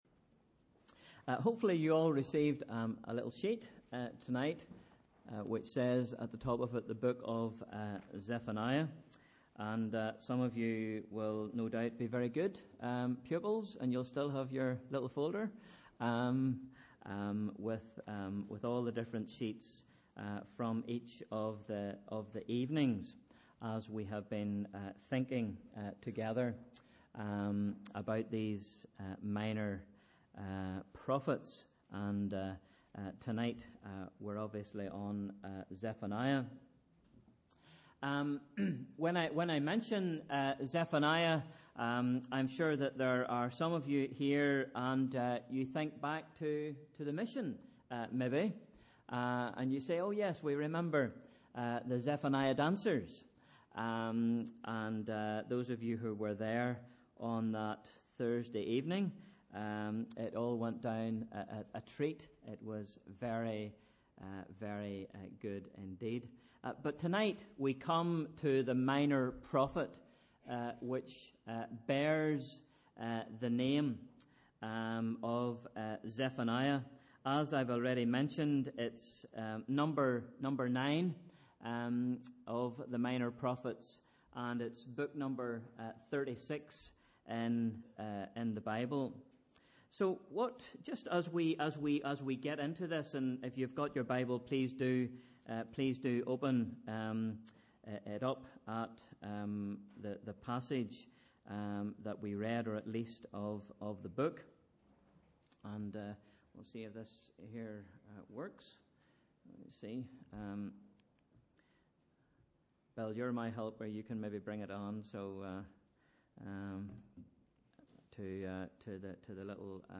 Sunday 19th July – Evening Service @ 7:00pm